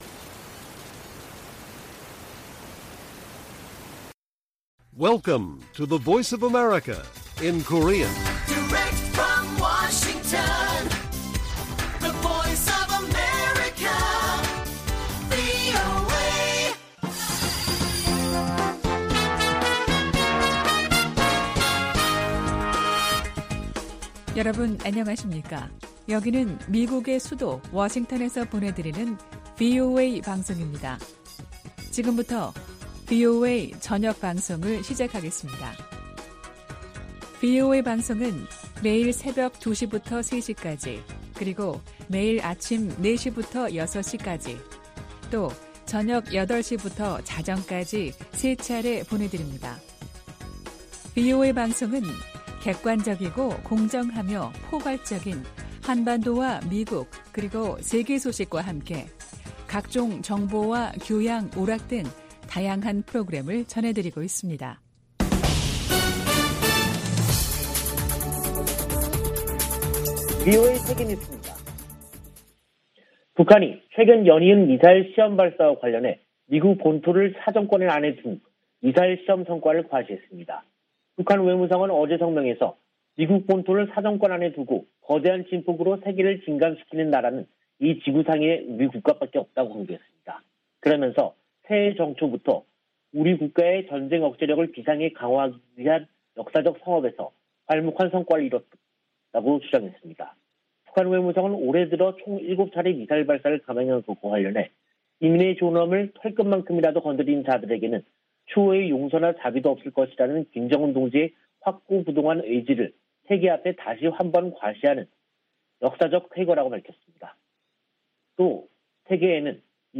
VOA 한국어 간판 뉴스 프로그램 '뉴스 투데이', 2022년 2월 9일 1부 방송입니다. 미 국무부는 대북 인도주의 지원을 위한 '제재 면제' 체제가 가동 중이라며, 제재가 민생을 어렵게 한다는 중국 주장을 반박했습니다. 유엔이 지원 품목을 제재 면제로 지정해도 북한의 호응을 얻지 못한 채 속속 기간 만료되고 있는 것으로 나타났습니다. 북한 대륙간탄도미사일(ICBM) 기지 완공이 처음 확인됐다고 미 전략국제문제연구소(CSIS)가 밝혔습니다.